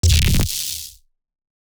OTT Artifact 3.wav